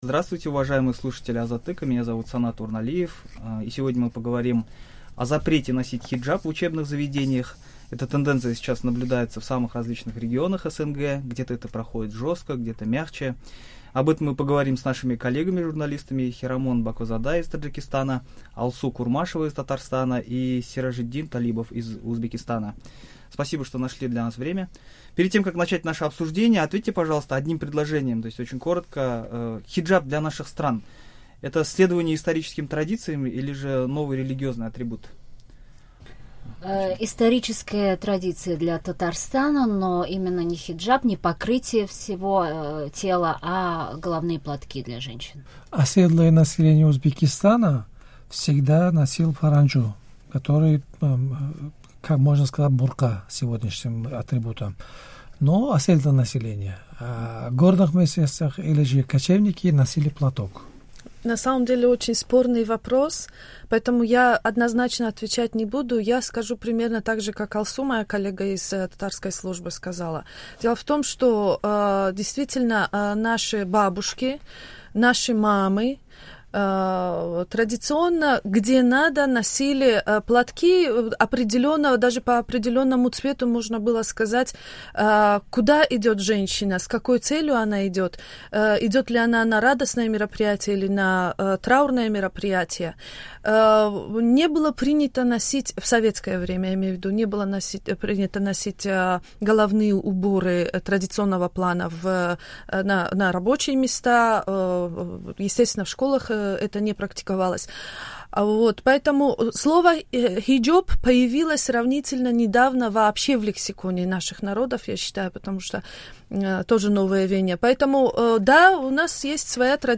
Азаттык обсуждает запрет носить хиджаб в учебных заведениях с журналистами из Узбекистана, Татарстана и Таджикистана, которые рассказали, как у них проходит эта ограничительная компания и как на это реагирует население.